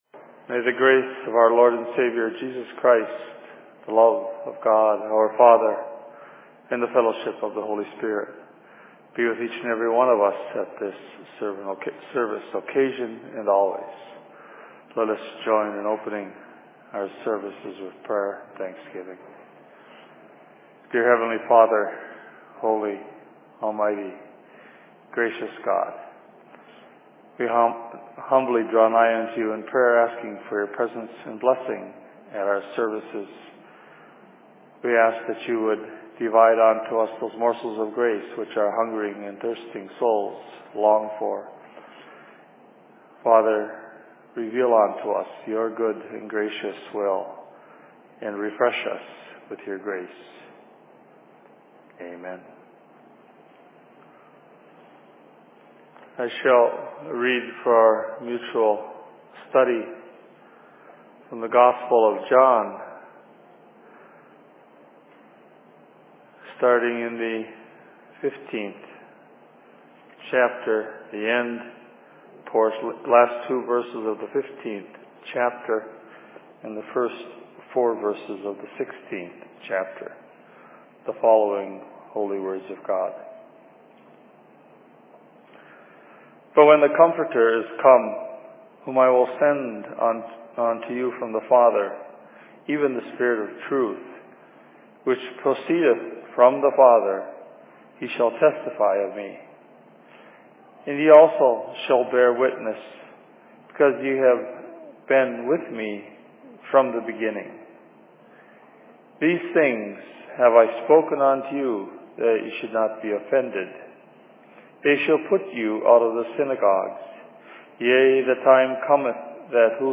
Sermon on Minneapolis 16.05.2010
Location: LLC Minneapolis